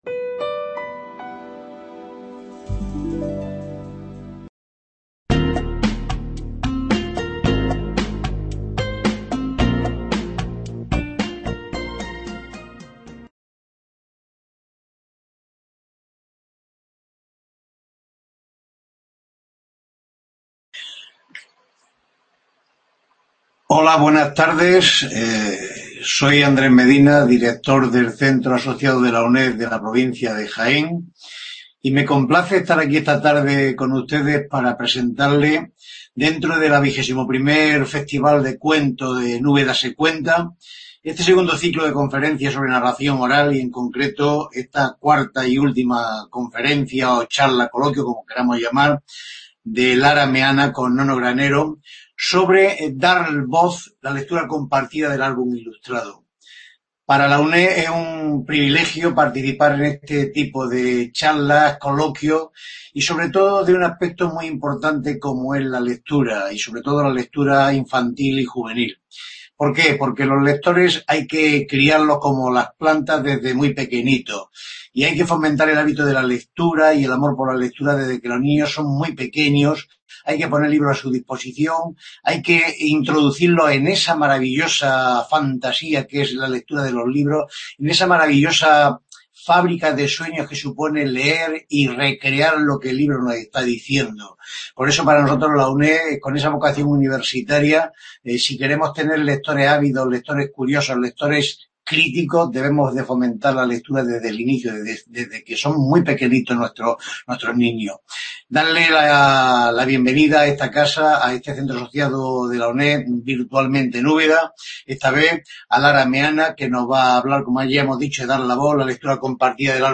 conferencia
2º Ciclo de Conferencias sobre Narración Oral del XXI Festival de cuentos "En Úbeda se cuenta" (2020)